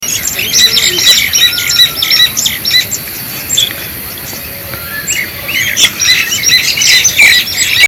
Bird